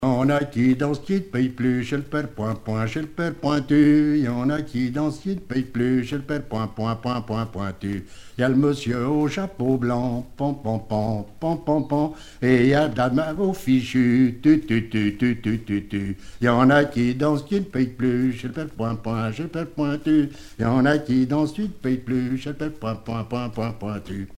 Chants brefs - A danser
danse : polka
Pièce musicale éditée